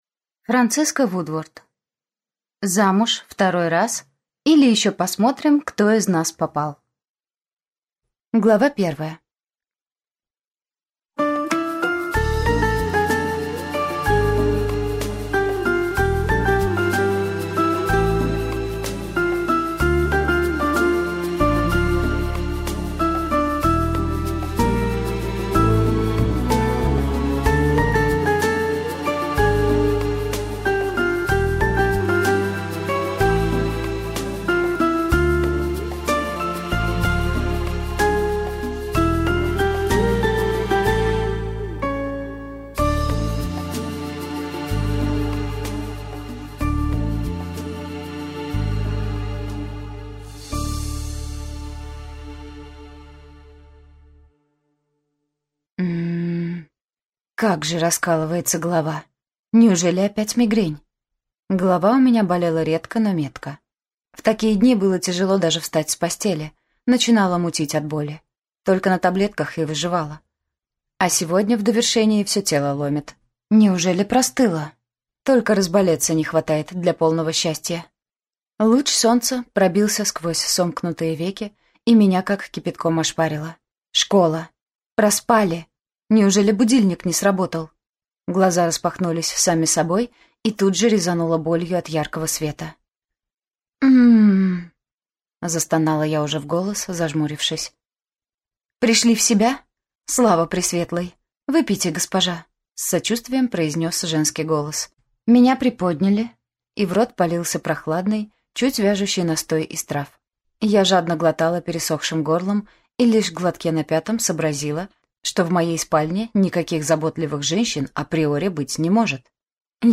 Аудиокнига Замуж второй раз, или Еще посмотрим, кто из нас попал!